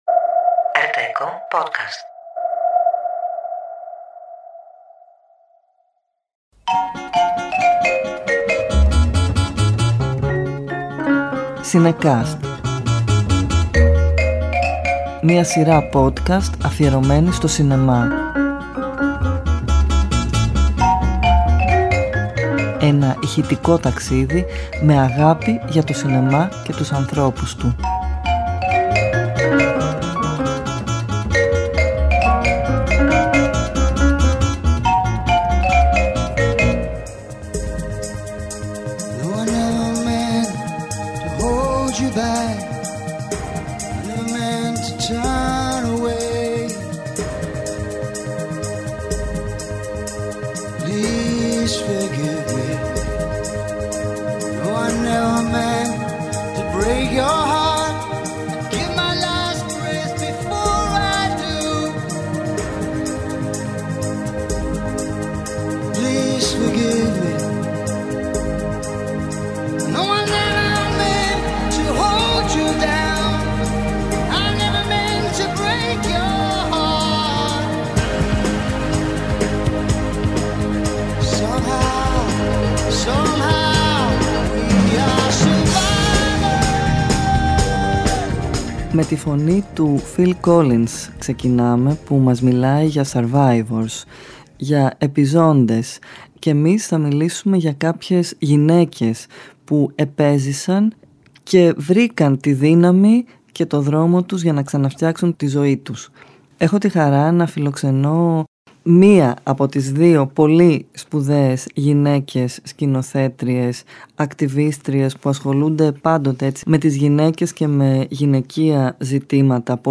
Κάθε επεισόδιο ζωντανεύει μια ταινία μέσα από συνεντεύξεις, αποσπάσματα και μουσικές.